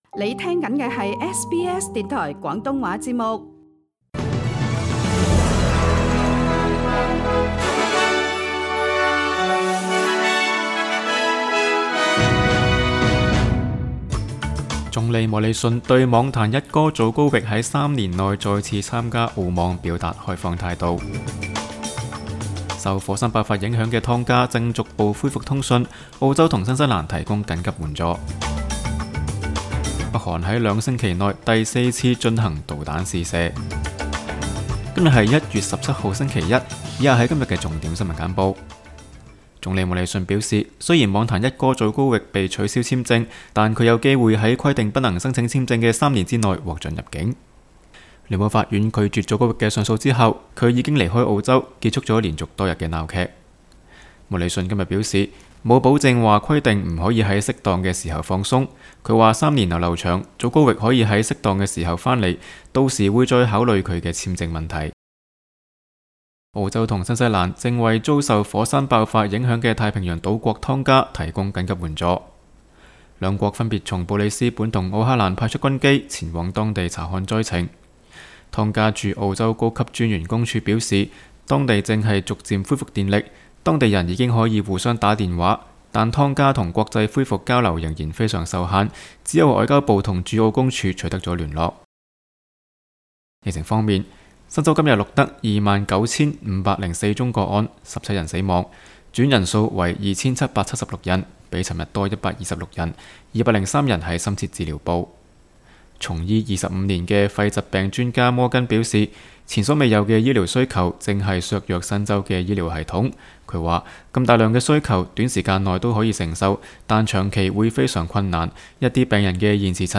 SBS 新聞簡報（1月17日）
SBS 廣東話節目新聞簡報 Source: SBS Cantonese